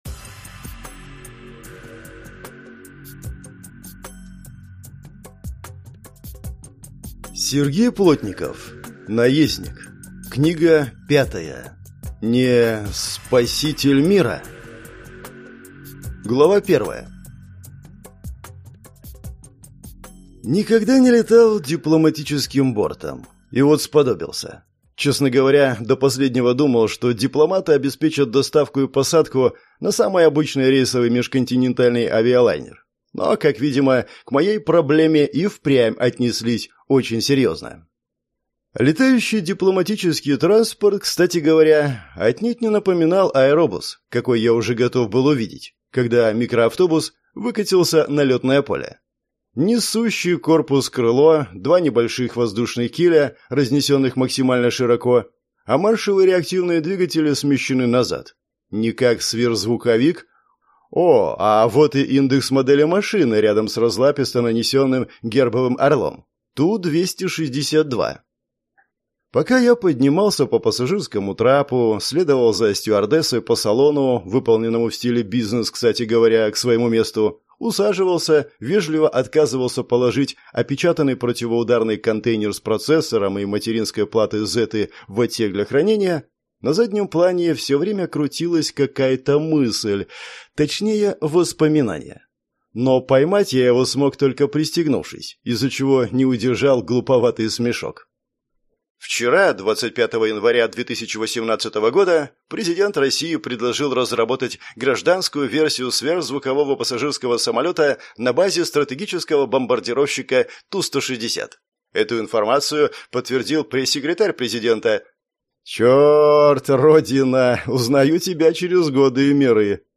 Аудиокнига Не… спаситель мира?!